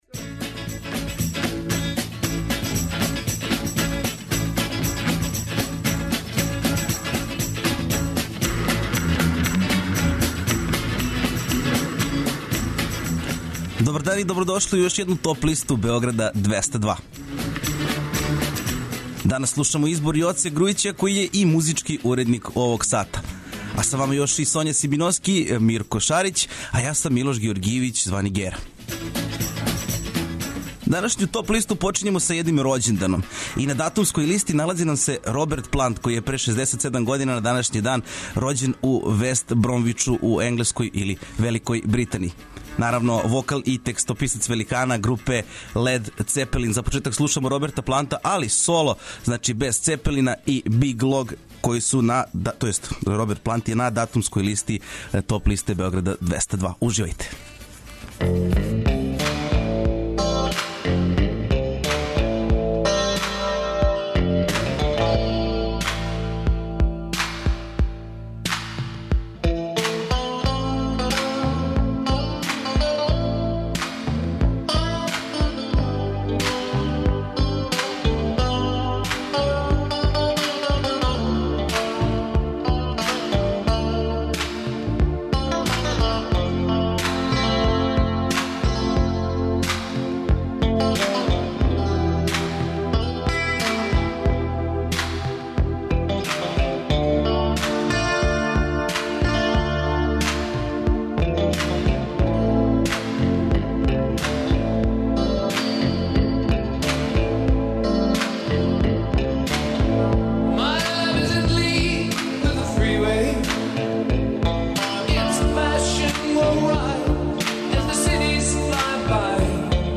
Најавићемо актуелне концерте у овом месецу, подсетићемо се шта се битно десило у историји рок музике у периоду од 17. до 21. августа. Ту су и неизбежне подлисте лектире, обрада, домаћег и страног рока, филмске и инструменталне музике, попа, етно музике, блуза и џеза, као и класичне музике.